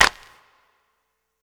Destroy - ThugPerc.wav